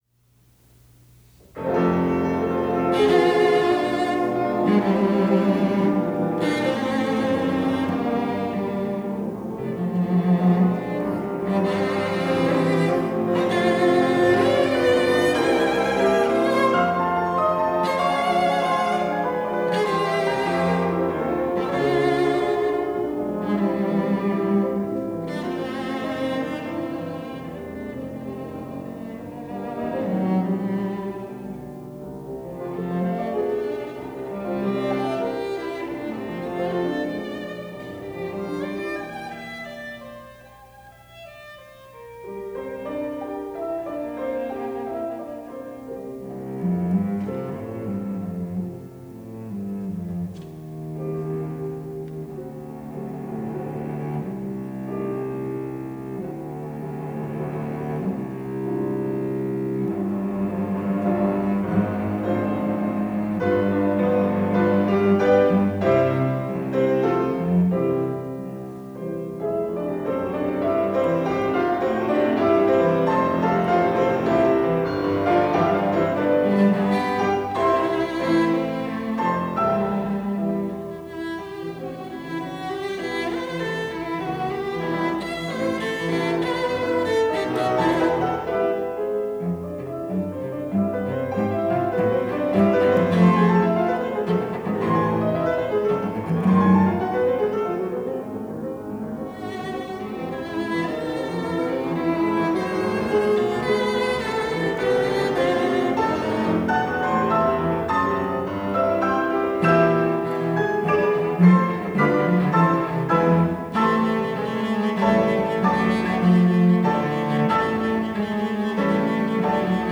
cello
Pianist